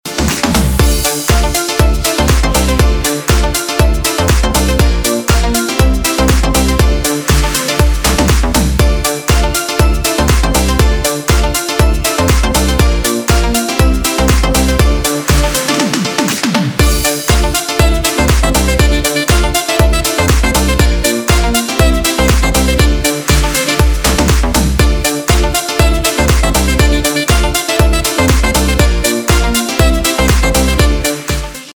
• Качество: 320, Stereo
громкие
remix
зажигательные
retromix
Cover
Саксофон
Зажигательный кавер на хит 80-х от русских диджеев